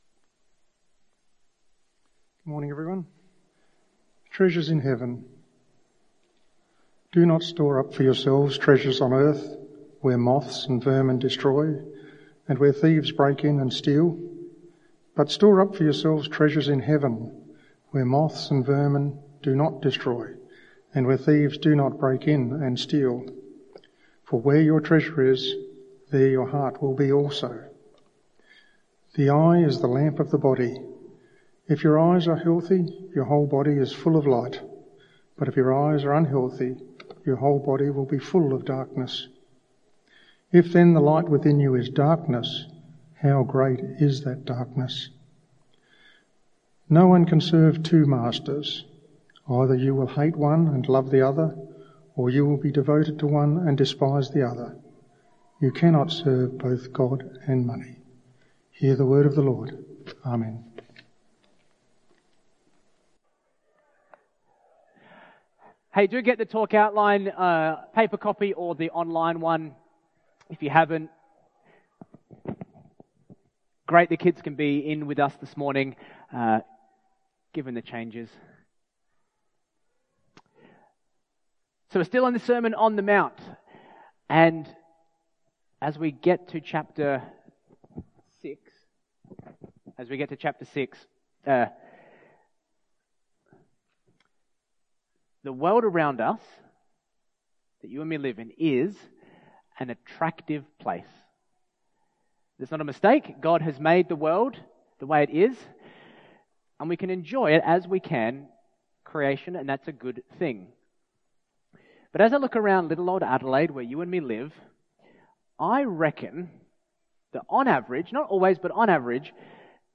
This Bible talk explores Jesus’ teaching on money and material possessions in Matthew 6:19-24.